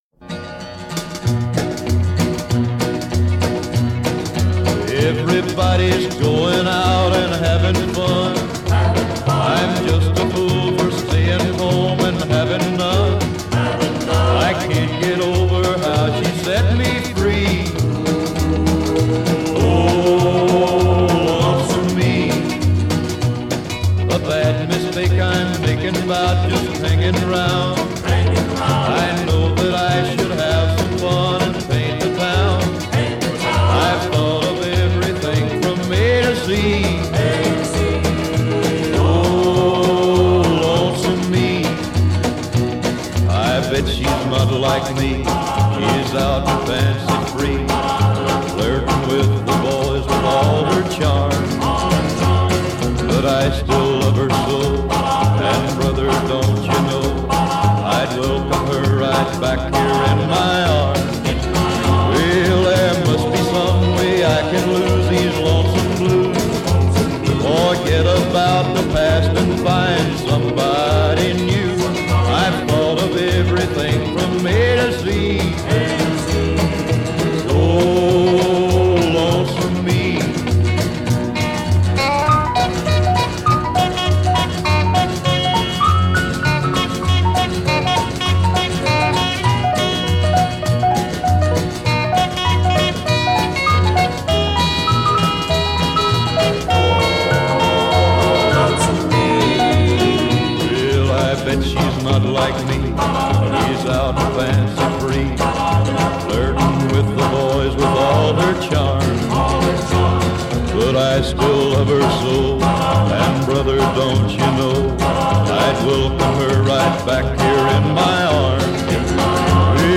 کانتری country